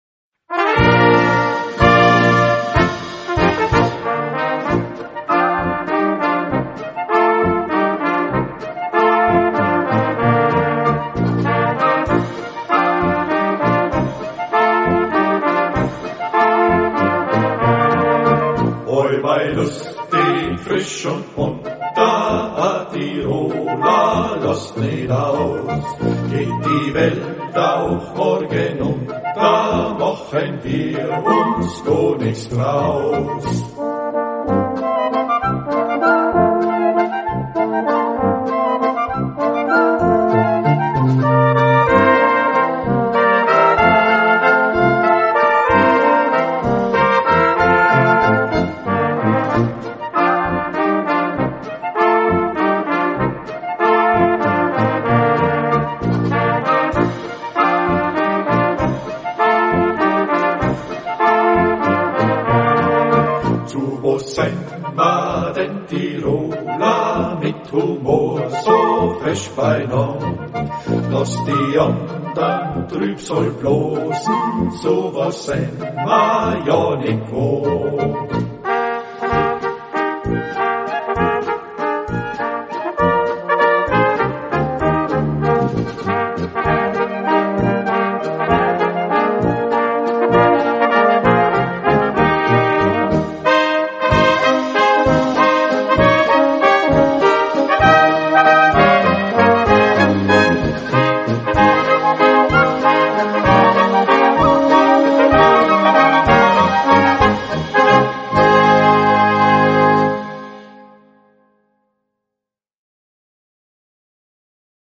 Gattung: Volkslied für böhmische Besetzung
Besetzung: Kleine Blasmusik-Besetzung
Auch hat er noch einen Jodler dazu komponiert.